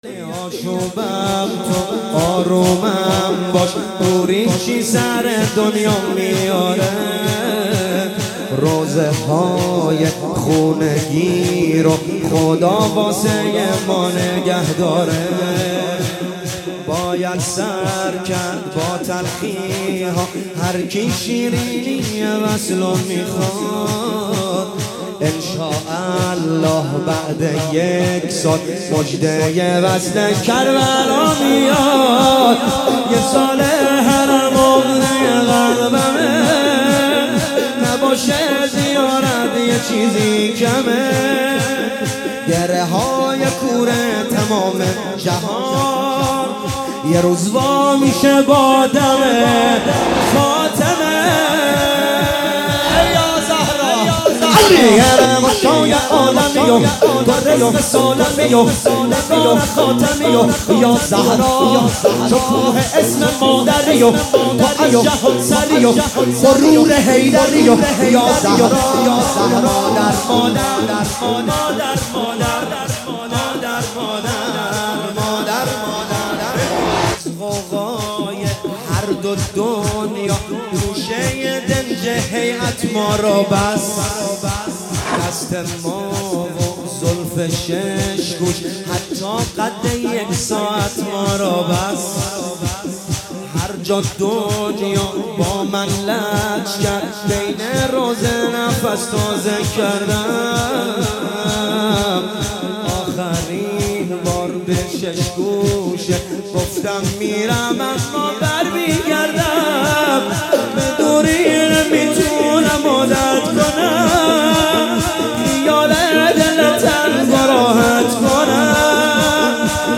ایام فاطمیه 1399 | هیئت عشاق الرضا (ع) تهران